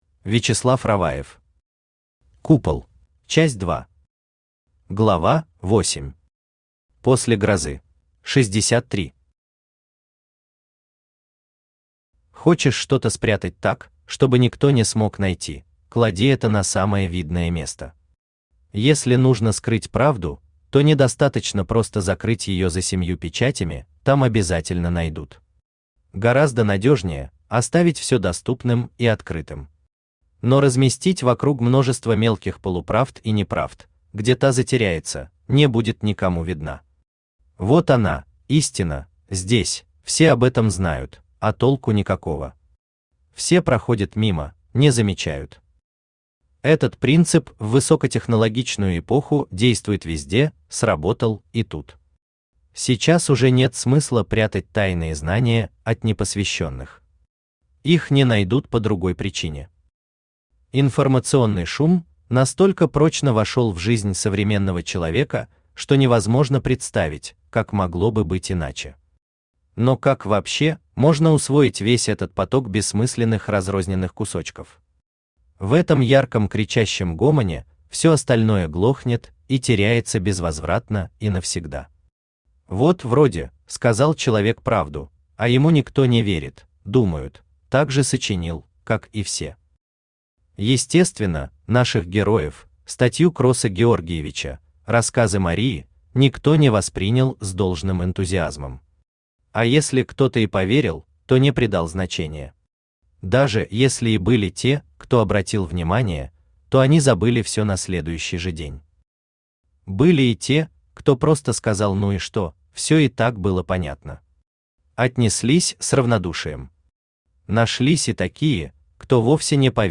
Аудиокнига Купол | Библиотека аудиокниг
Aудиокнига Купол Автор Вячеслав Анатольевич Раваев Читает аудиокнигу Авточтец ЛитРес.